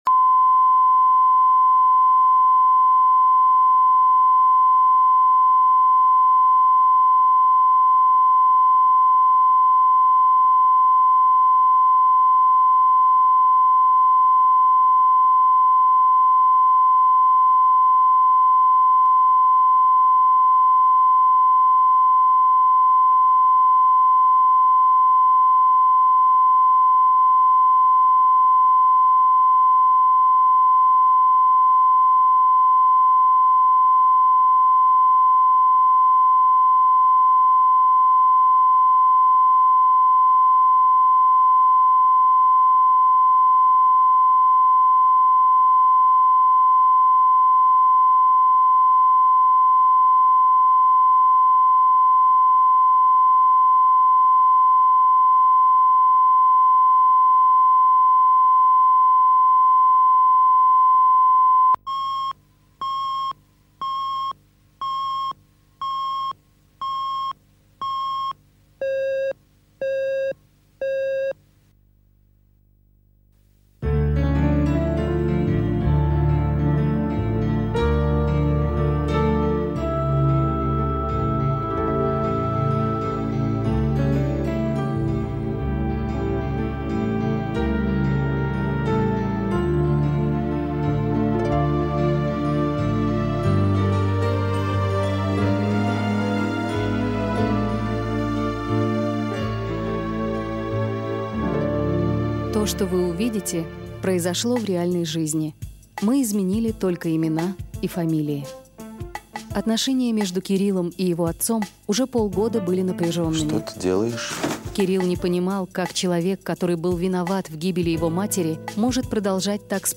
Аудиокнига Любовница отца